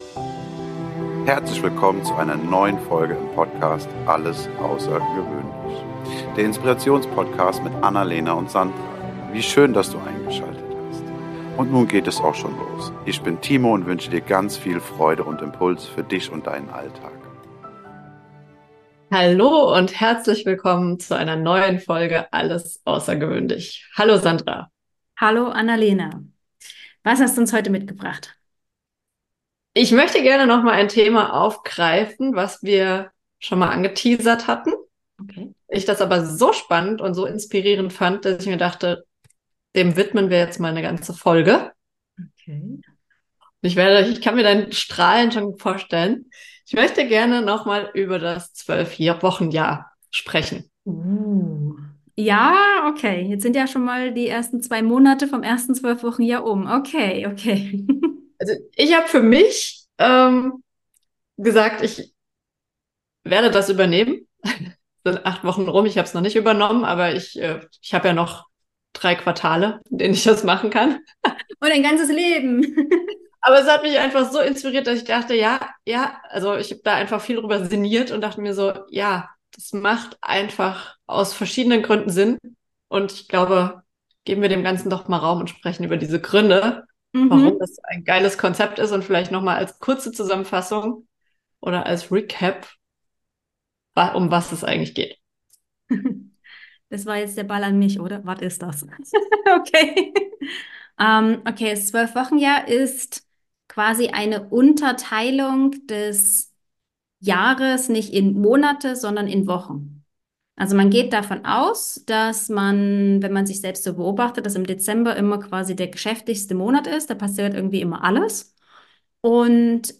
angeregten Unterhaltung über Zeitmanagement, Routinen und Selbstorganisation